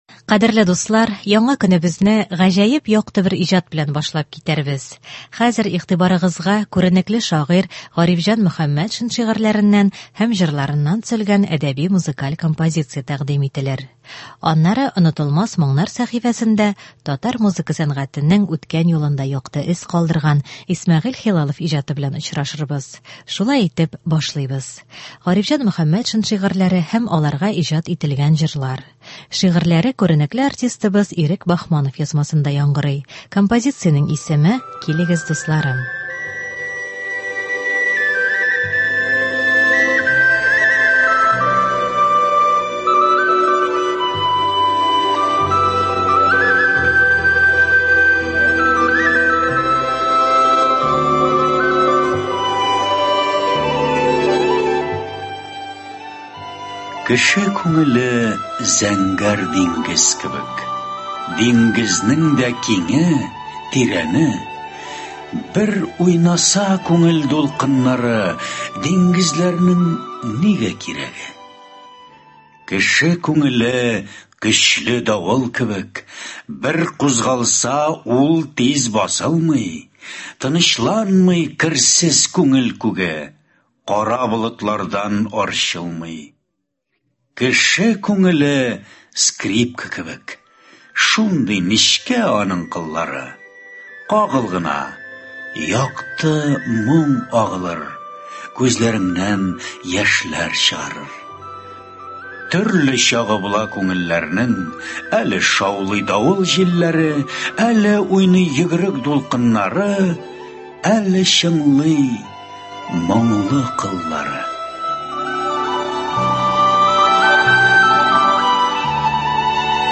Гарифҗан Мөхәммәтшин әсәрләреннән әдәби-музыкаль композиция.